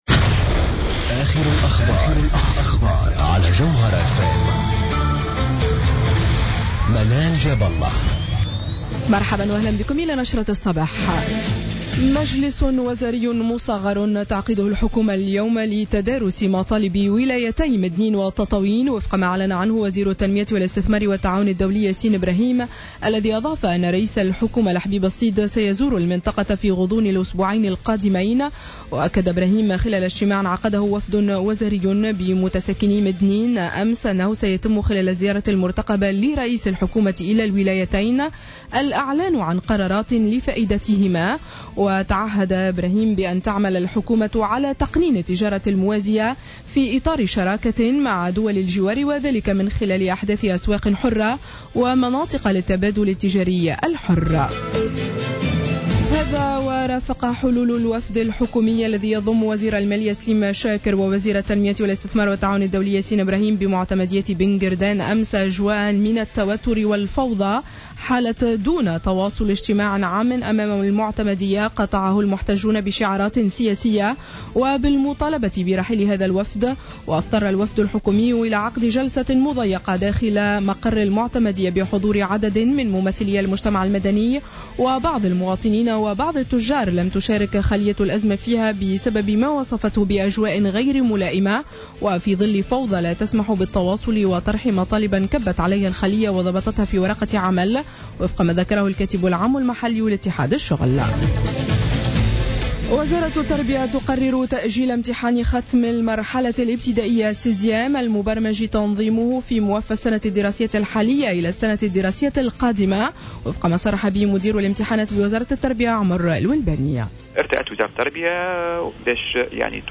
نشرة أخبار السابعة صباحا ليوم الجمعة 13 فيفري 2015